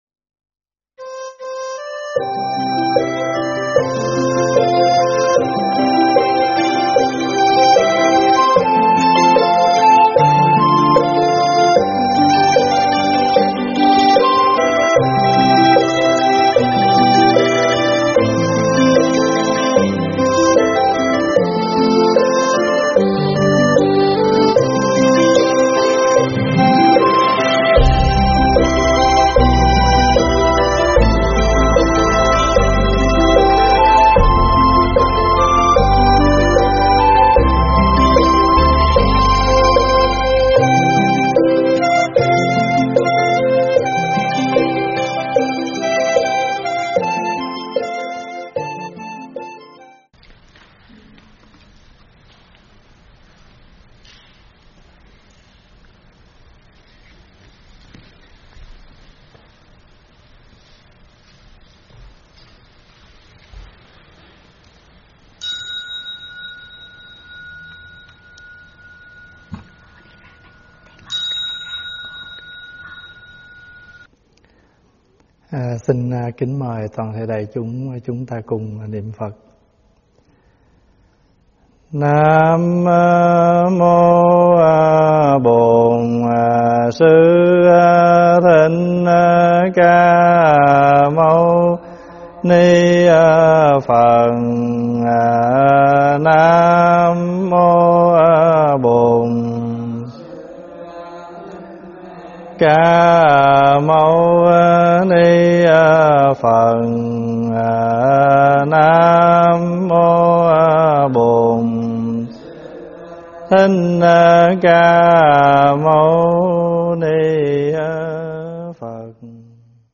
Mời quý phật tử nghe mp3 thuyết pháp Thiền Tịnh Dung Thông
giảng tại Tv.Trúc Lâm